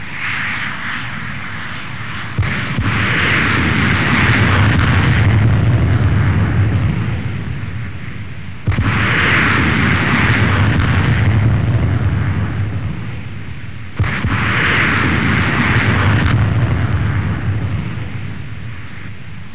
دانلود آهنگ طیاره 54 از افکت صوتی حمل و نقل
جلوه های صوتی
دانلود صدای طیاره 54 از ساعد نیوز با لینک مستقیم و کیفیت بالا